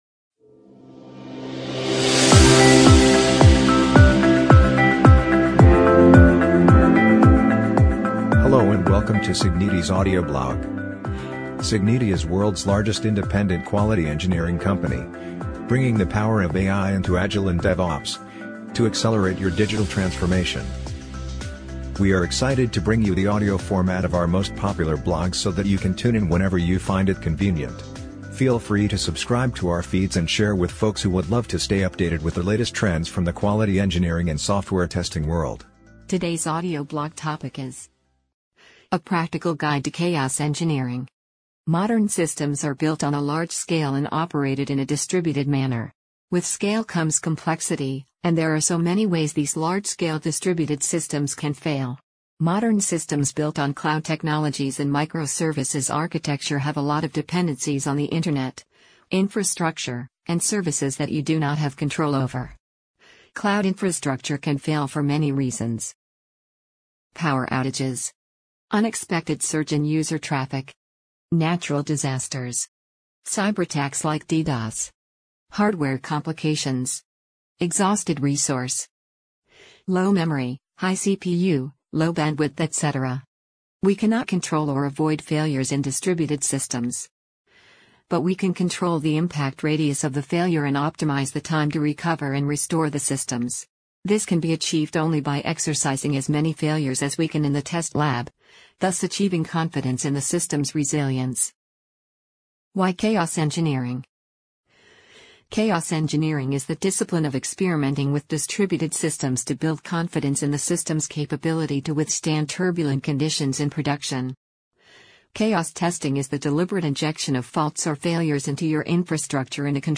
amazon_polly_15338.mp3